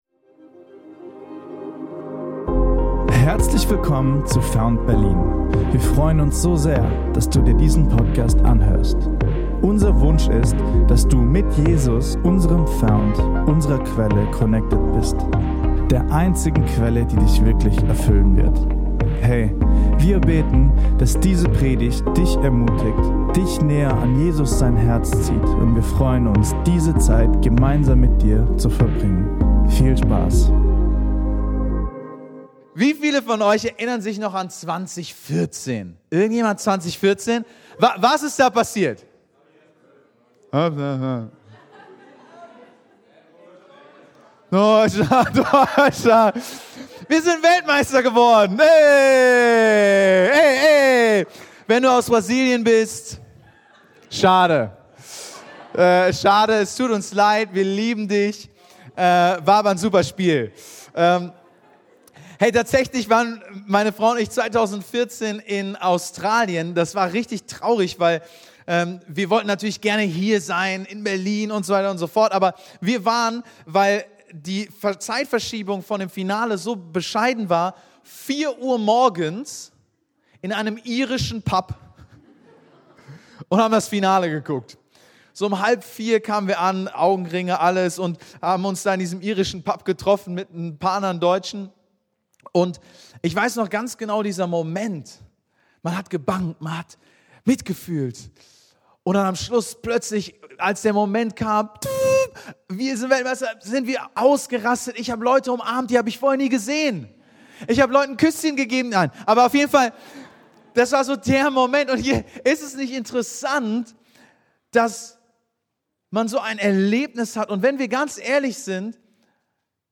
Hast Du dich schonmal gefragt was echte Einheit ist und wie du sie in Dein Leben bringen kannst? — Höre in dieser Predigt